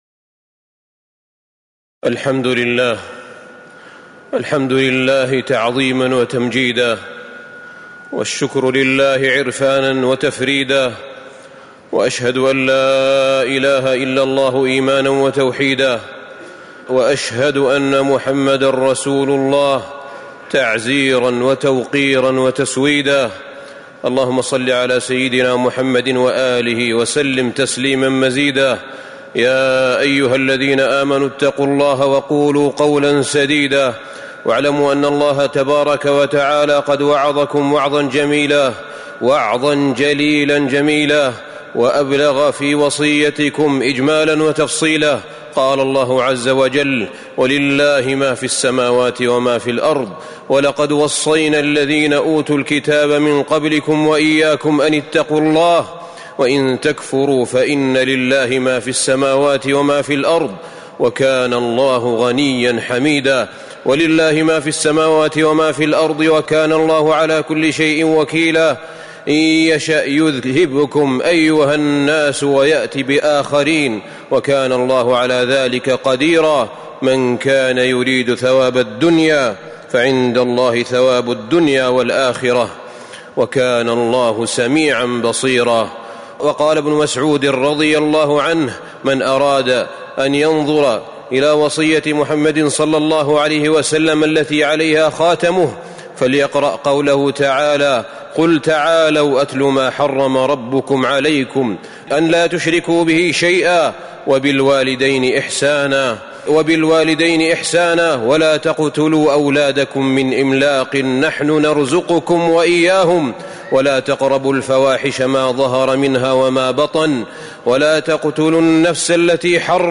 تاريخ النشر ٢٩ ذو الحجة ١٤٤٥ هـ المكان: المسجد النبوي الشيخ: فضيلة الشيخ أحمد بن طالب بن حميد فضيلة الشيخ أحمد بن طالب بن حميد من وصايا الأنبياء عليهم الصلاة والسلام